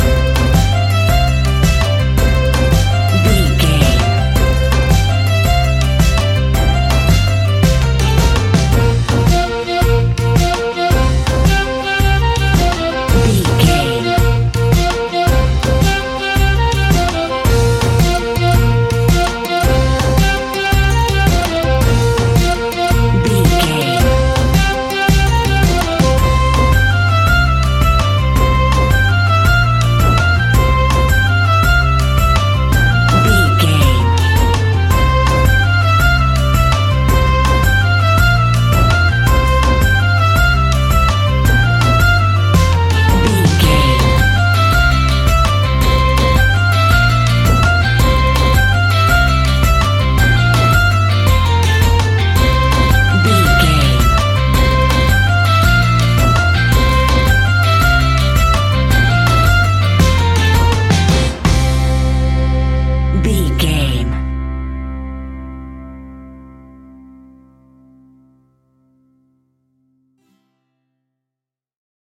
Mixolydian
sea shanties
earthy
acoustic guitar
mandolin
ukulele
drums
double bass
accordion